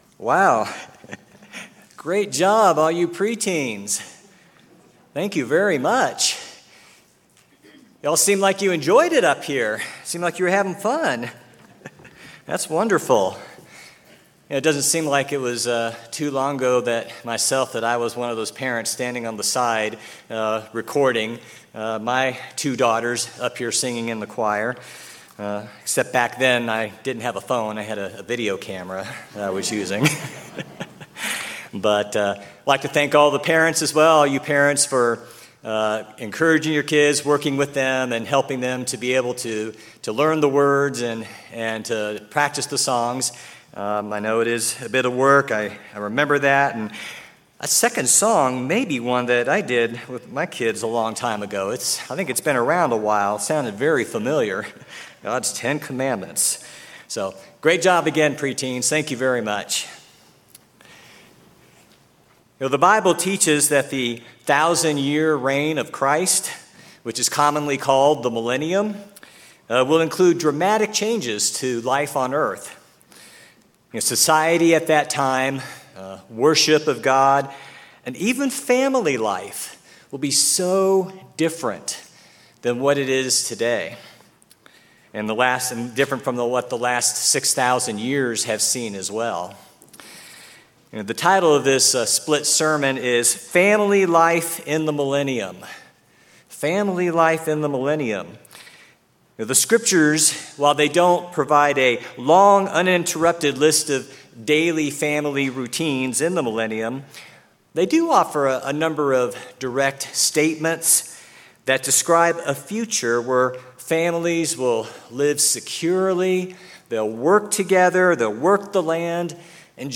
Sermons
Feast of Tabernacles
Given in Klamath Falls, Oregon